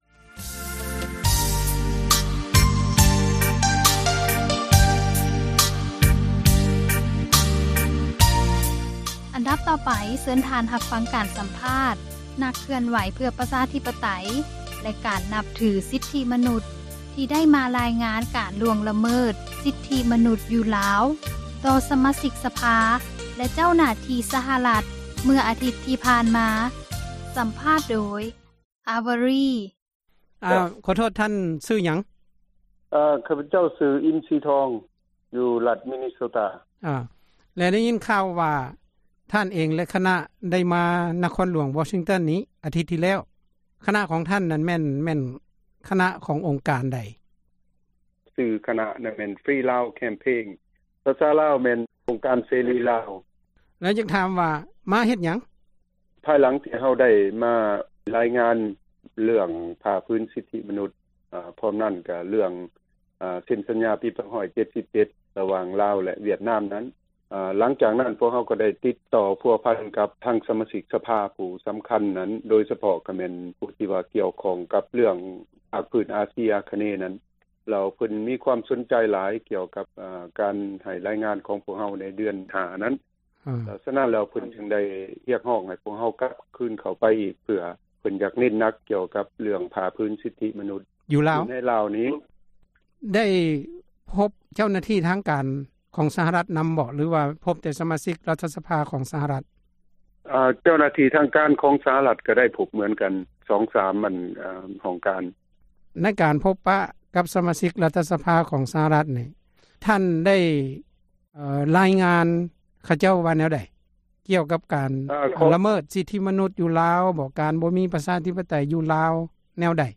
ສຳພາດ ນັກເຄື່ອນໄຫວ ເພື່ອ ປະຊາທິປະໄຕ ແລະ ການນັບຖື ສິດມະນຸດ ທີ່ໄດ້ມາ ຣາຍງານ ການລ່ວງ ຣະເມີດ ສິດທິ ມະນຸດ ຢູ່ລາວ ຕໍ່ ສະມາຊິກ ສະພາ ແລະ ເຈົ້າໜ້າທີ່ ສະຫະຣັດ ໃນອາທິດ ທີ່ ຜ່ານມາ.